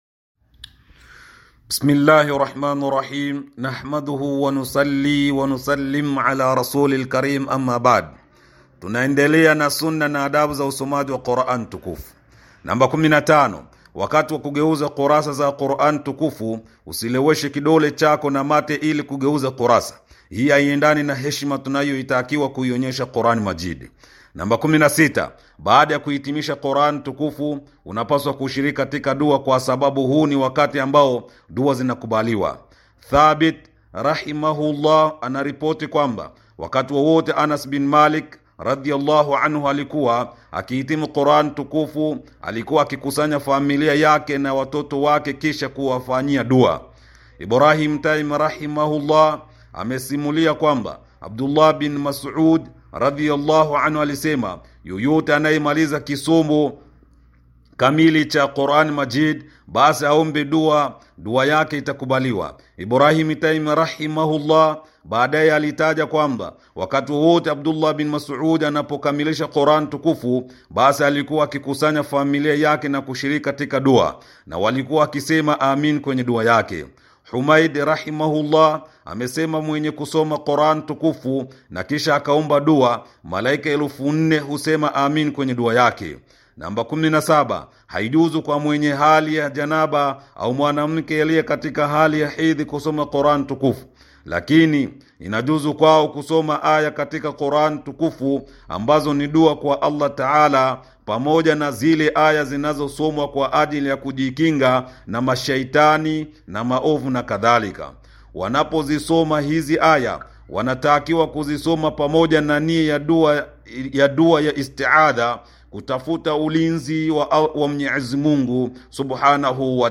tilaawat.mp3